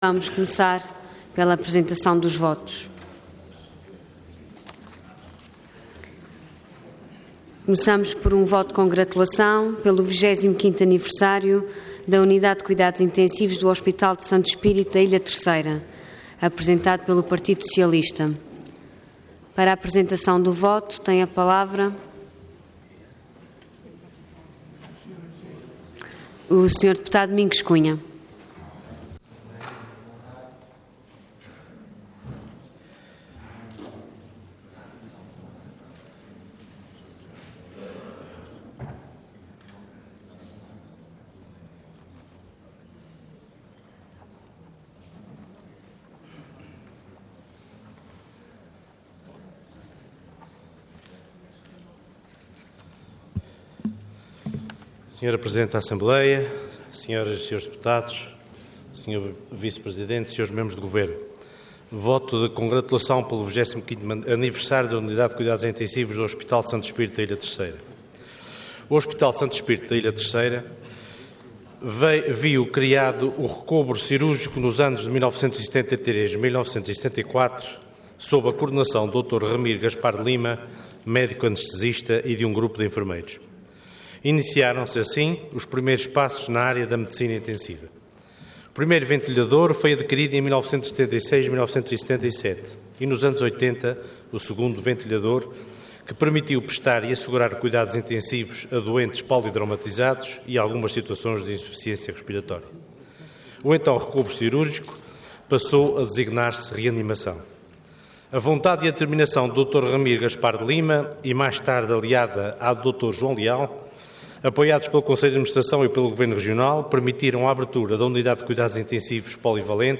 Intervenção Voto de Congratulação Orador Domingos Cunha Cargo Deputado Entidade PS